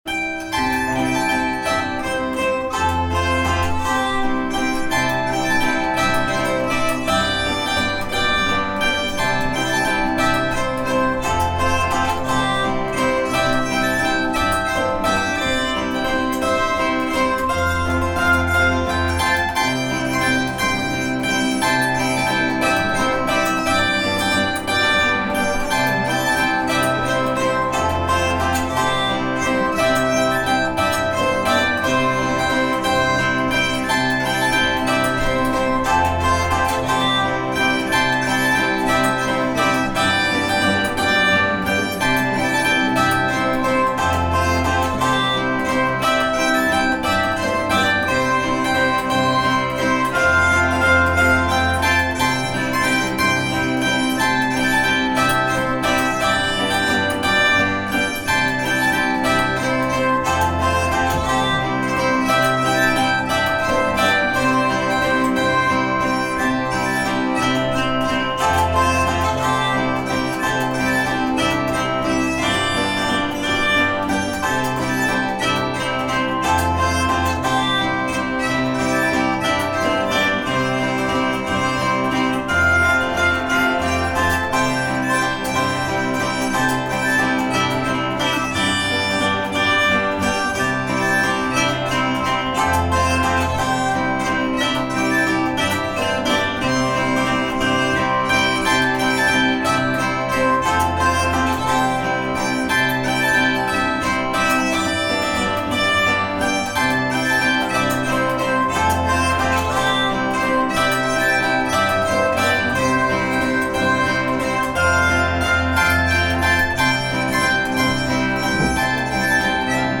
Recorded October 17, 2004 at Wylie House Museum, Bloomington, Indiana
Phonoharp No. 2 1/4 chord-zither, Bosstone ukelin